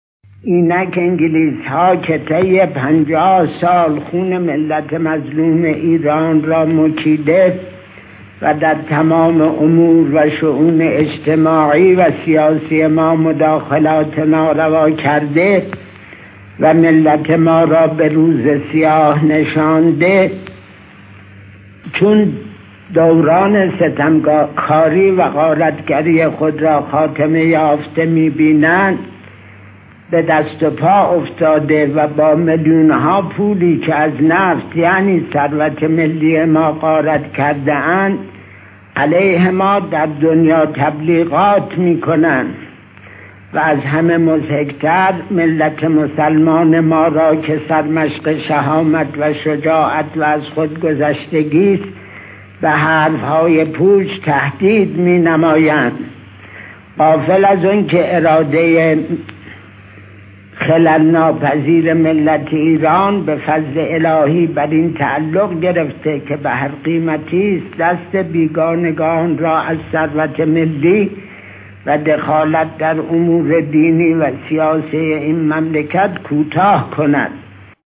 انتشار صوت سخنرانی‌های تاریخی
نطق‌های آتشین دکتر محمد مصدق و آیت‌الله ابوالقاسم کاشانی در دوره هفدهم مجلس شورای ملی و درباره صنعت نفت از جمله سخنان تاریخی‌ ادوار مختلف مجلس است.
این سخنان در دوره هفدهم مجلس شورای ملی، بین سال‌های 1330 تا 1332 و در جریان بحث‌های مربوط به ملی شدن صنعت نفت ایران بیان شده است.